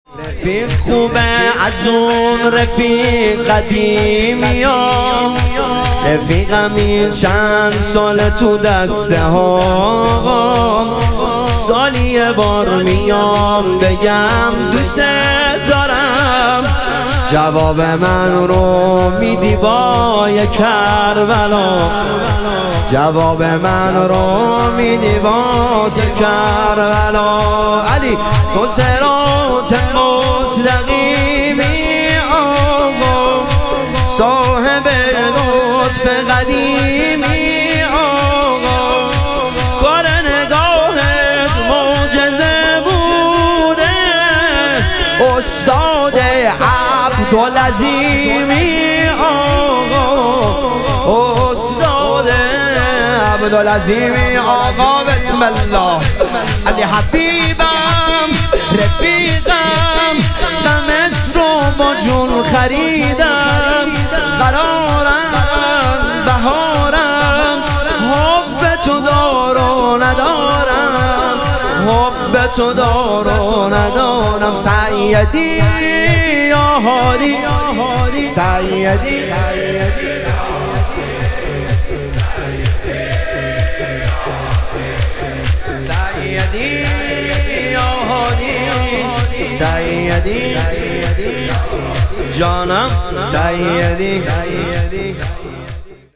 شهادت امام هادی علیه السلام - شور